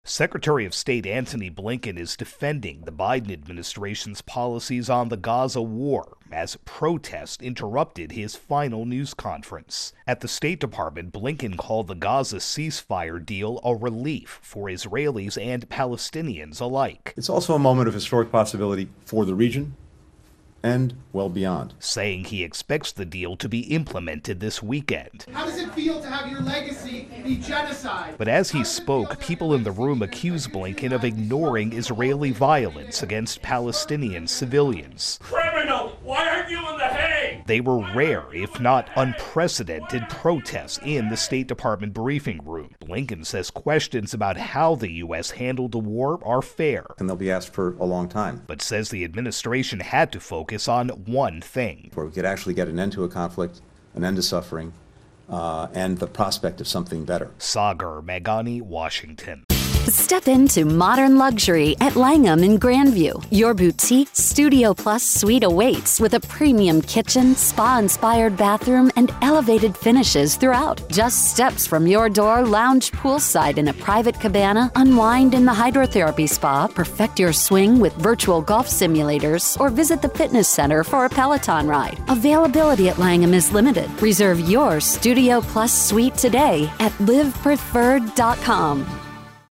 Latest Stories from The Associated Press